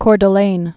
(kôr də-lān, kôrdl-ān, kûrd-)